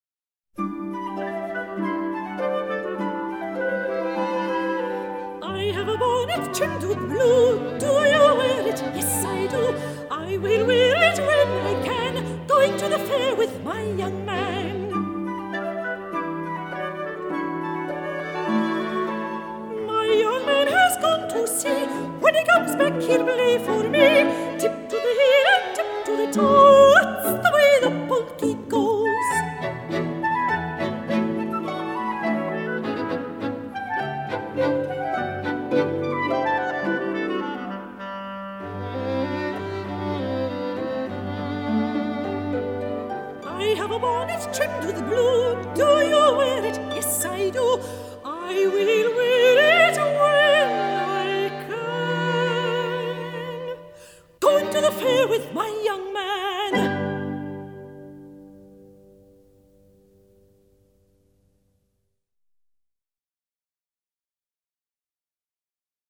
她擁有非常迷人的抒情女高音音色，帶著特殊的母性和溫柔，這樣的音色很少能在近代歐美學院訓練體系中找到，要感謝她的紐西蘭出身，這裡的修女保有一套老式的聲樂訓練法，那是第一次世界大戰前訓練出眾多老式女高音的手法。
在這張專輯中為您所選出的推薦歌曲是I Have a Bonnet Trimmed With Blue，就是最近一值在電視廣告中強烈播送的金色奇異果的廣告歌曲，廣告中演唱者的聲音聽起來應該是費莉亞，不過我這裡只有卡娜娃演唱的版本，前者音色柔美，後者音色雄壯，是為其異也！